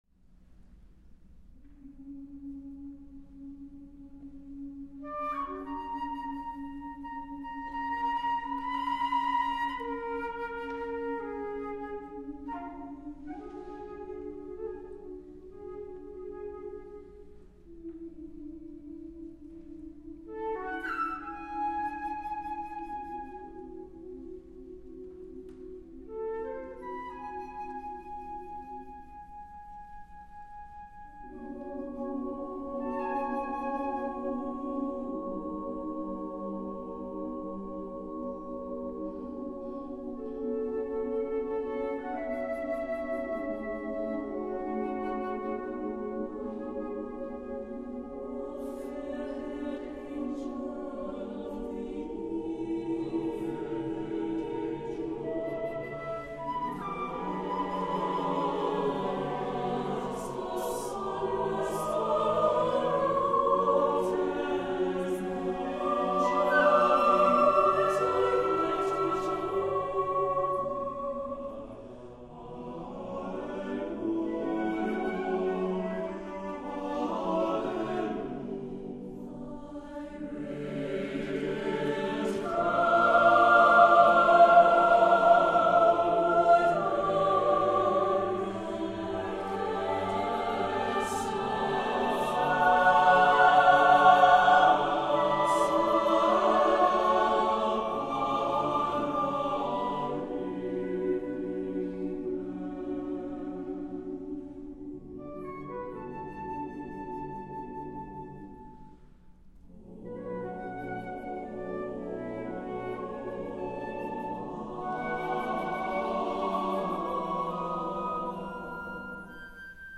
CHORAL
SATB a cappella